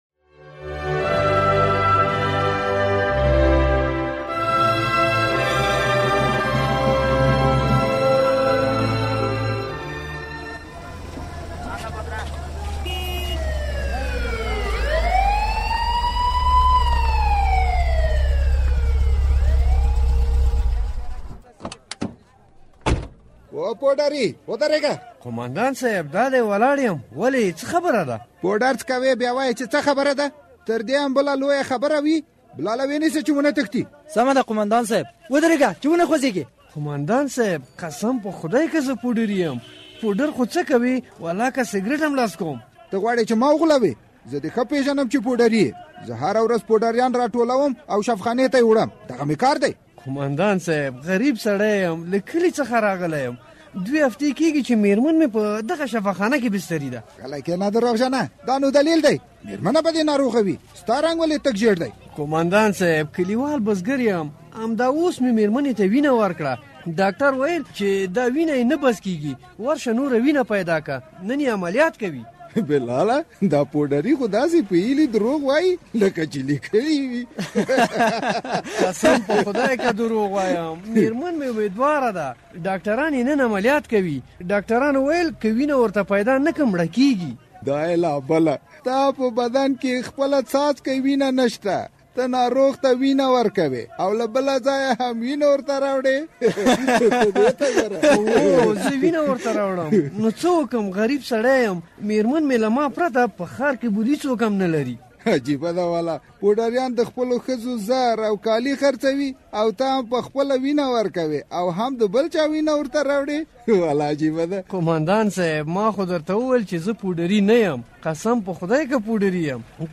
د زهرو کاروان ډرامه؛ د دين محمد مېرمن ولې مړه شوه؟